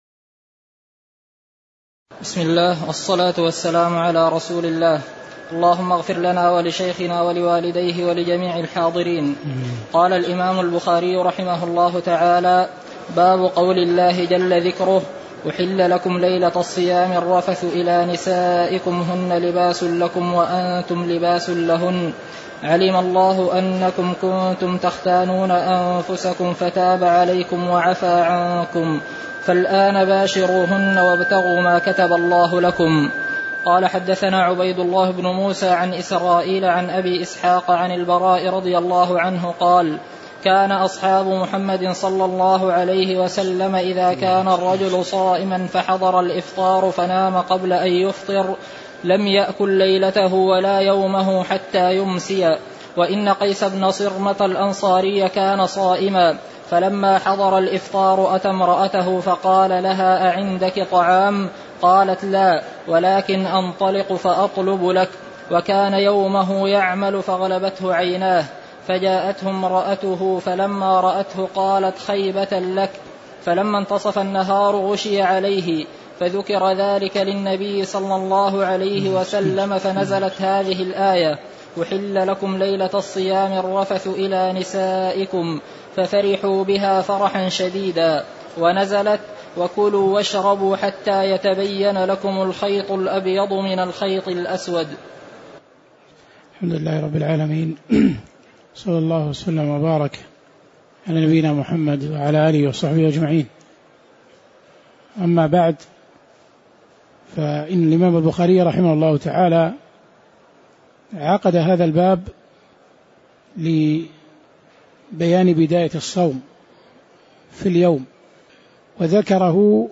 تاريخ النشر ٥ رمضان ١٤٣٨ هـ المكان: المسجد النبوي الشيخ